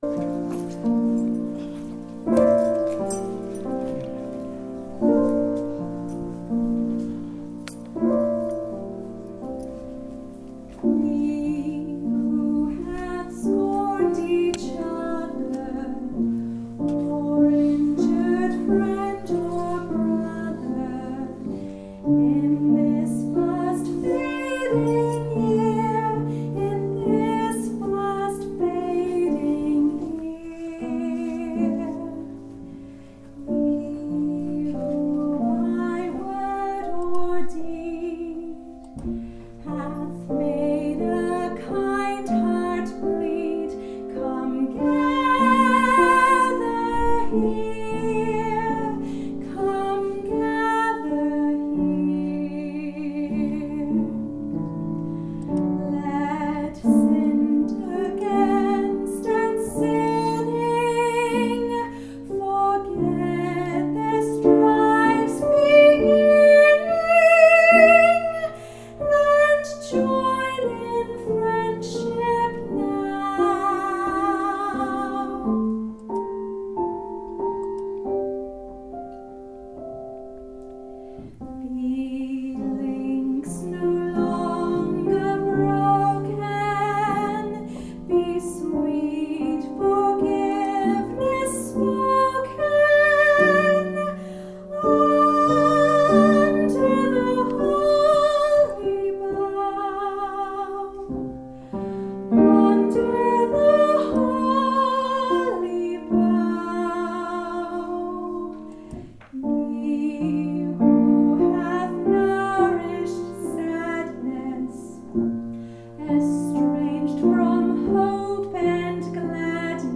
piano
contemporary piece